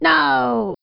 voice_no.wav